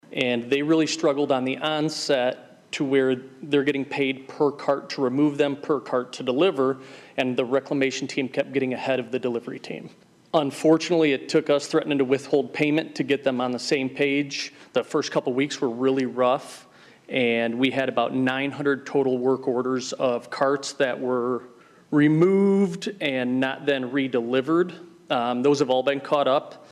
THE CITY COUNCIL OF SIOUX CITY APPROVED TWO MEASURES INVOLVING GILL HAULING, THE CITY’S TRASH AND RECYCLING COLLECTION COMPANY ON MONDAY, BUT NOT WITHOUT A LIVELY DISCUSSION ABOUT THE QUALITY OF THEIR SERVICE.